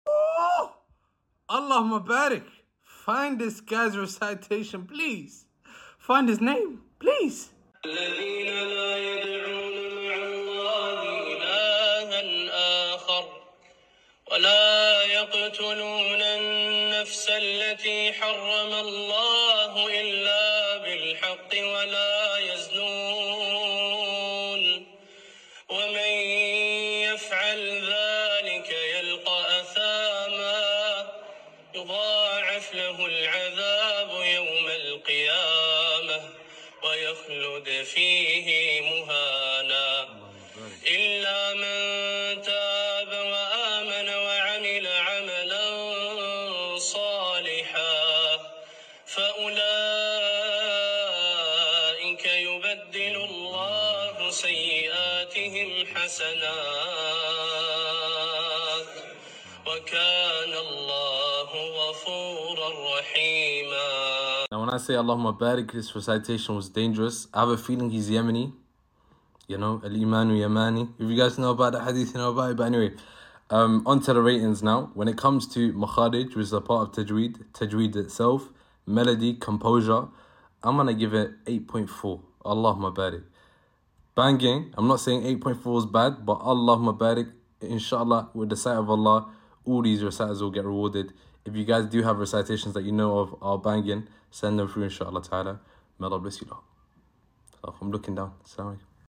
Unknown Yemeni Quran reciter sound effects free download
The recitation was beautiful and adheres to tajweed may Allah preserve him and protect him from any harm.